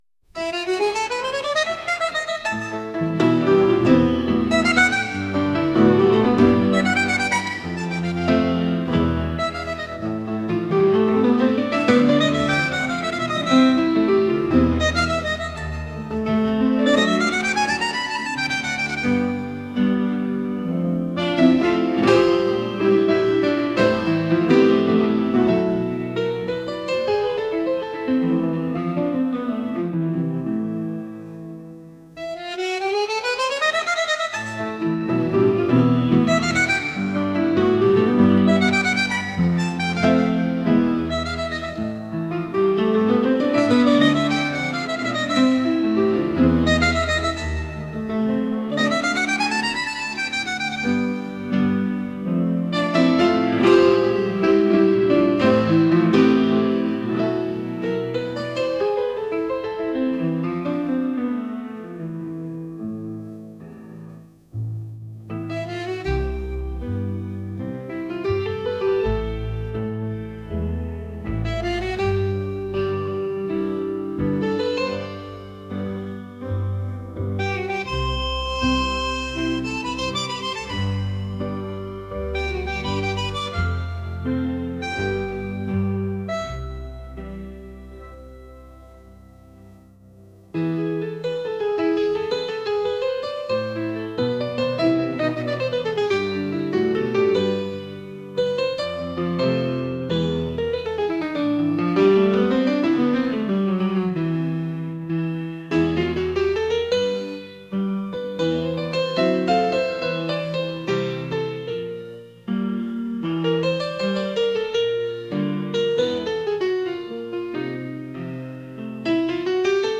latin | romantic | pop